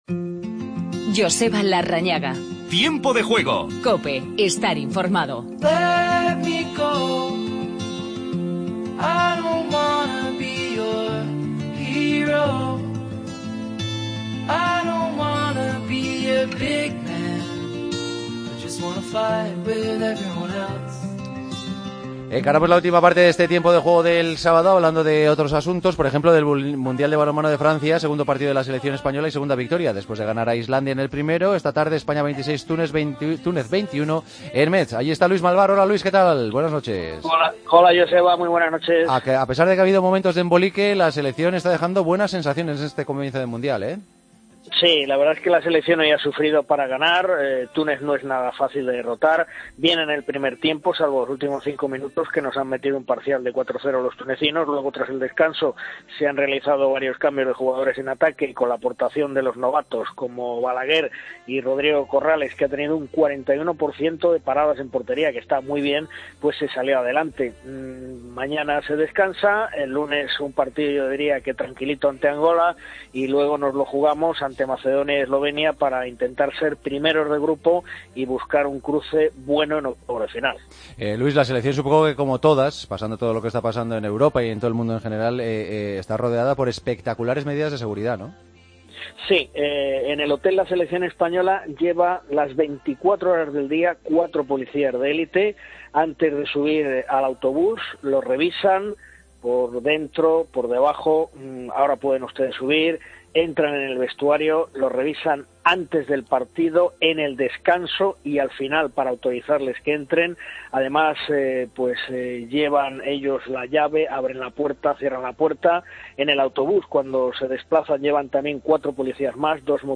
Entrevista con Nani Roma.